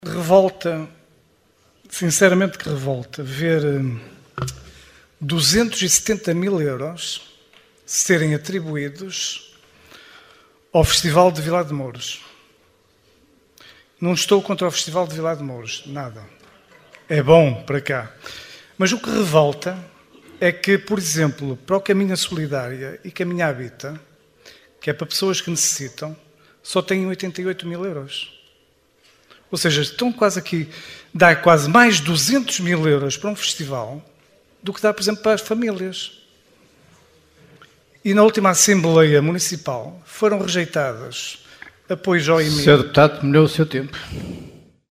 Excertos da última assembleia municipal de Caminha, de 16 de dezembro de 2022.